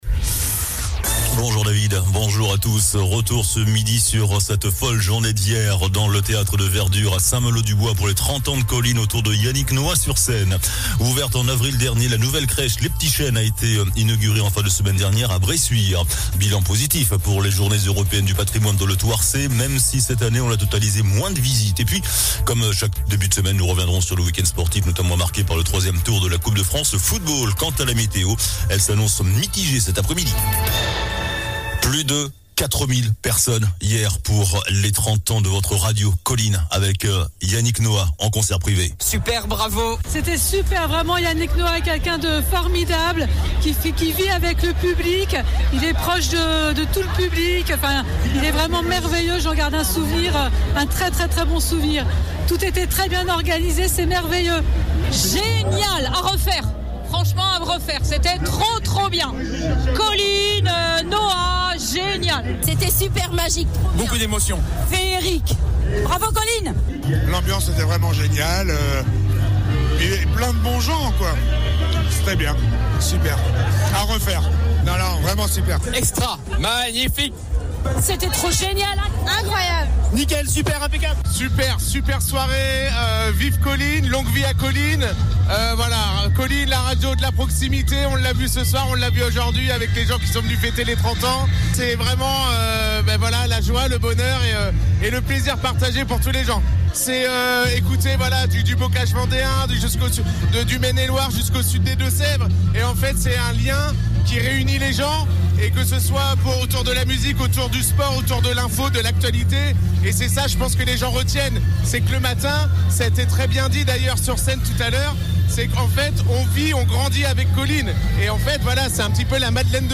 JOURNAL DU LUNDI 18 SEPTEMBRE ( MIDI )